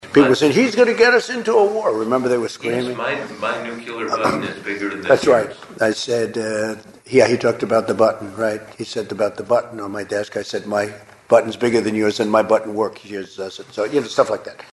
During a December 2019 Oval Office interview with then-President Donald Trump, Washington Post journalist Bob Woodward asked whether his bellicose rhetoric toward North Korean leader Kim Jong Un had been intended to drive Kim to the negotiating table.